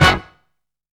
TRAIN HIT.wav